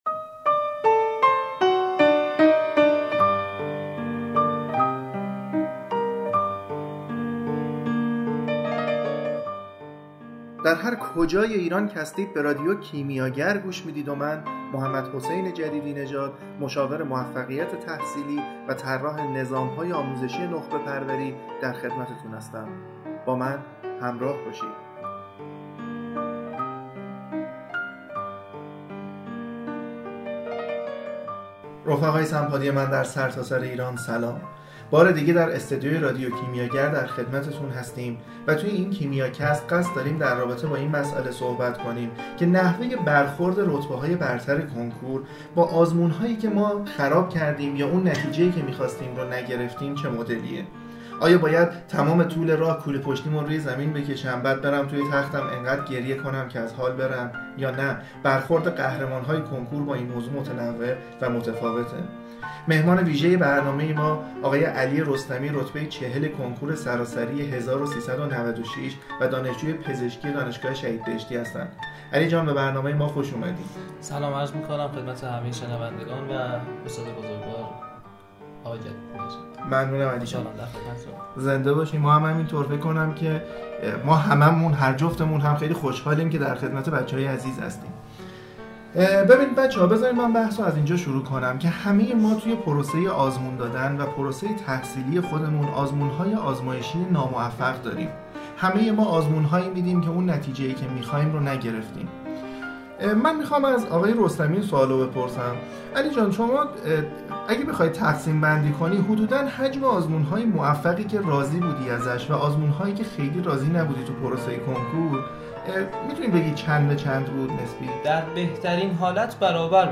مصاحبه با رتبه های برتر/